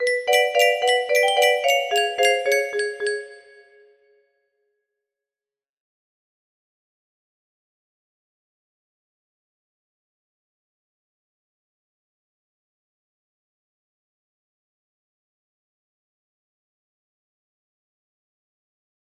Sample music box melody